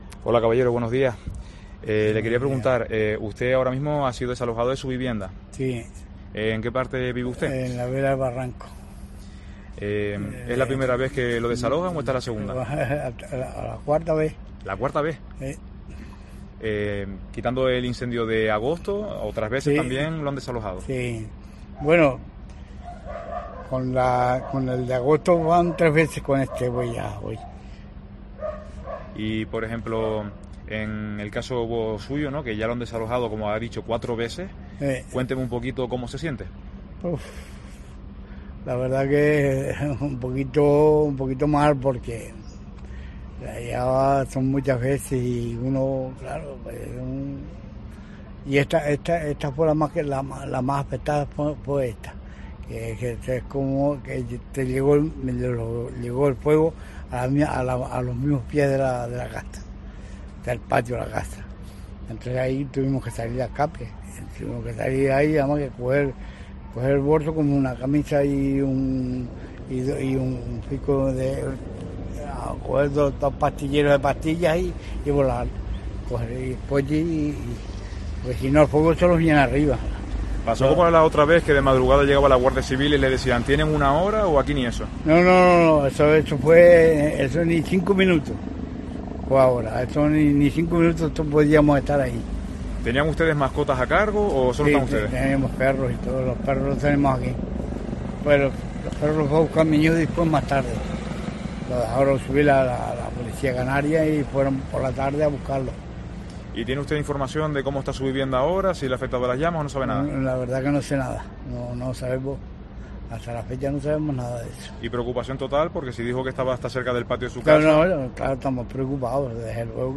Uno de los vecinos desalojados de La Corujera contaba en COPE cómo vive su tercer desalojo desde el verano por el incendio: "Esta vez fue la más dura, porque el fuego llegó a los pies de casa.